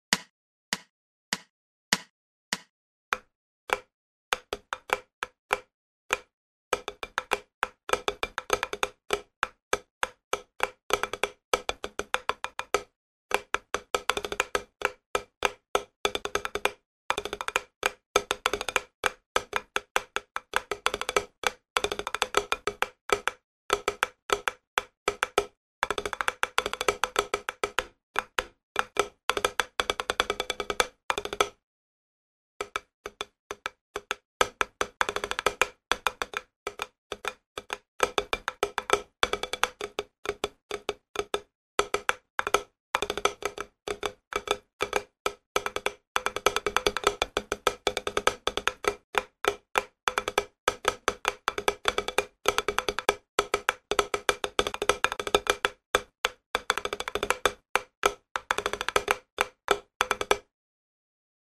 Рудиментальный этюд
Этюд №38 - в основе рудимент "Флэм"
Размер 3/4, темп - 100 bpm, сыгран без реприз